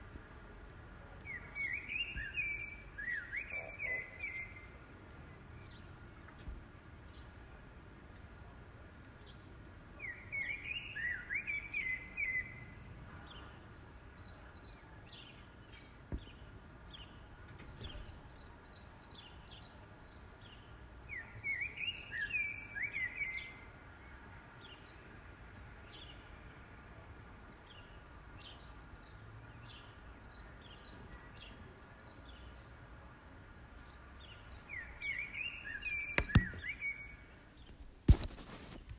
Voor alle vogelgeluidenliefhebbers
maar ik moet zeggen dat deze vogel nog best een gevarieerd deuntje doet, in plaats van hetzelfde elke 3 seconden.
Vogelspottervriend zegt: ‘dit is een mannetjesmerel die z’n territoriumdeuntje zingt’.